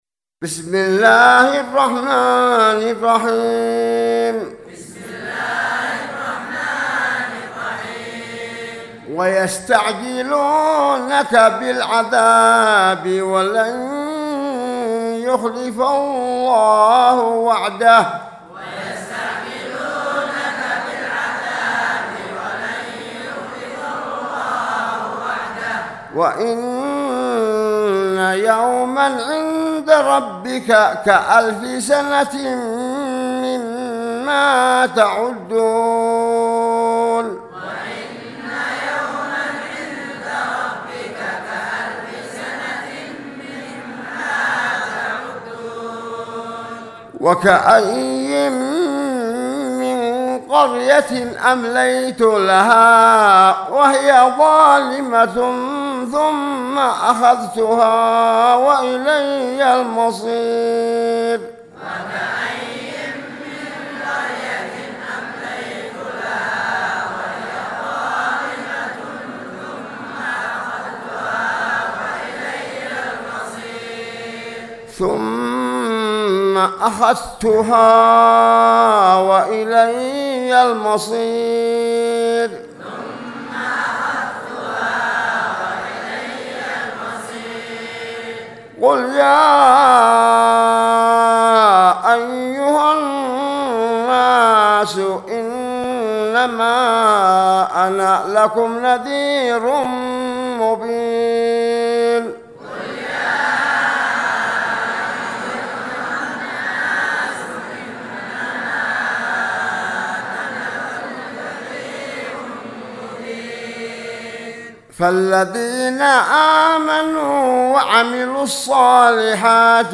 سورة الحج مع الترديد من 47 الى 55